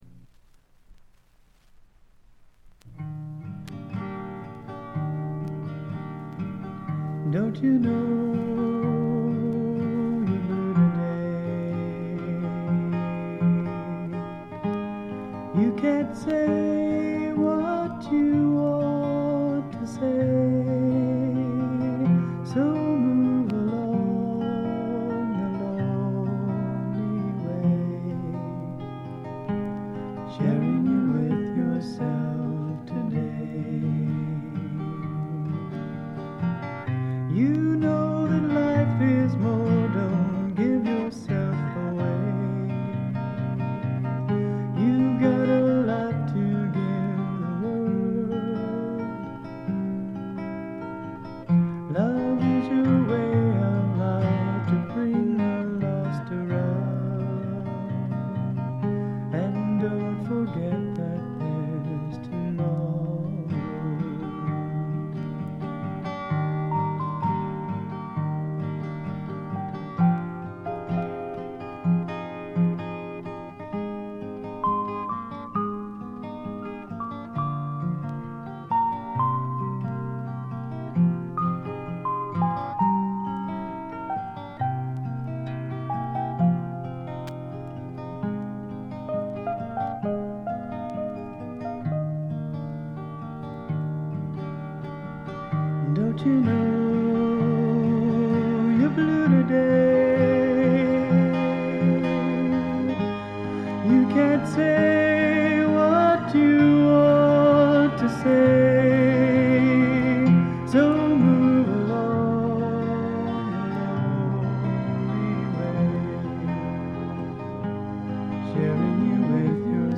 軽いバックグラウンドノイズ。
ローナーフォーク、ドリーミーフォークの逸品です。
試聴曲は現品からの取り込み音源です。